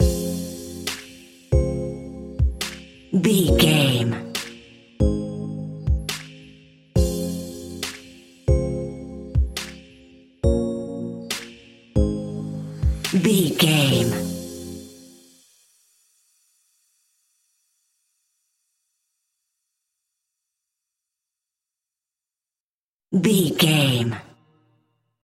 Aeolian/Minor
Slow
hip hop
chilled
laid back
groove
hip hop drums
hip hop synths
piano
hip hop pads